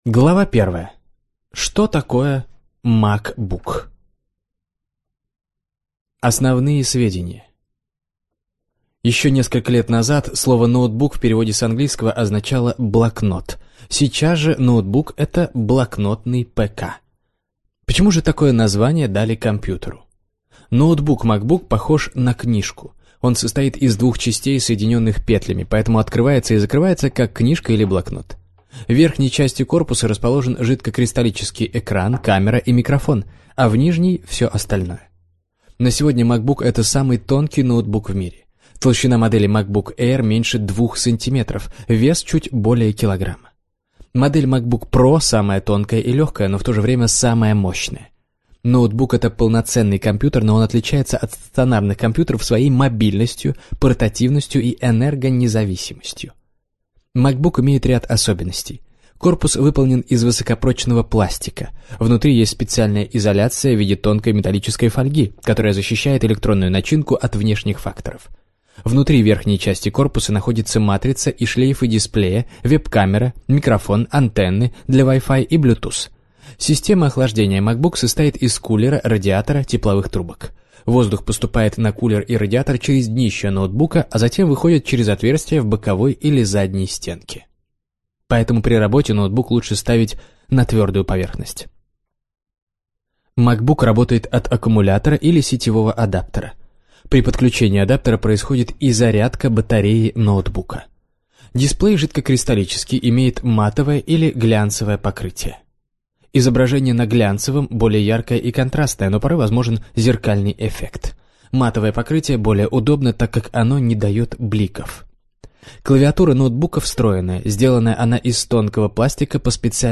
Аудиокнига Самоучитель MacBook | Библиотека аудиокниг